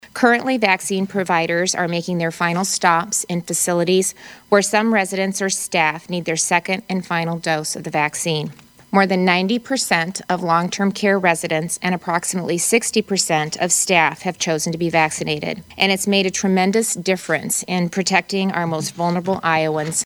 REYNOLDS SAYS MOST OF IOWA’S LONG TERM CARE FACILITIES HAVE COMPLETED VACCINATIONS: